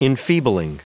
Prononciation du mot enfeebling en anglais (fichier audio)
Prononciation du mot : enfeebling